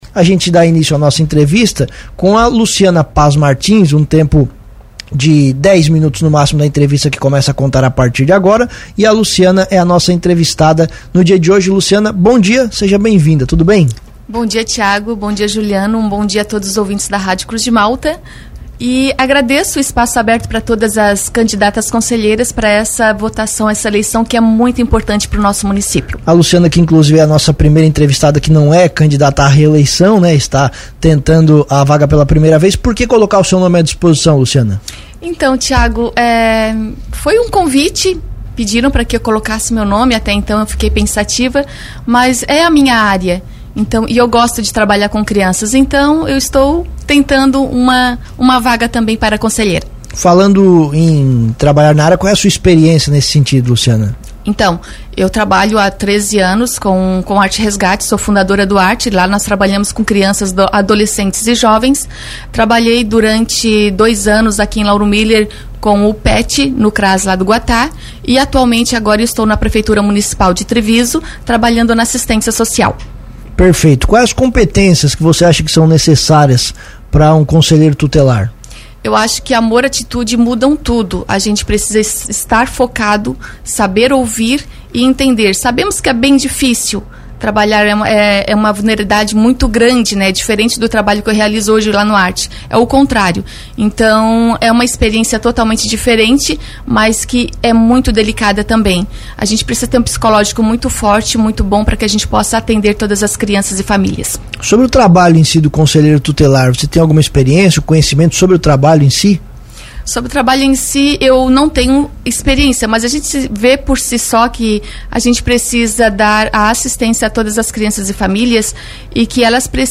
As entrevistas vão ao ar todos os dias a partir das 8h, com tempo máximo de 10 minutos cada.